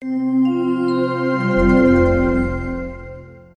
• Качество: высокое
Мелодия завершения работы Windows 98